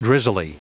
Prononciation du mot drizzly en anglais (fichier audio)
Prononciation du mot : drizzly
drizzly.wav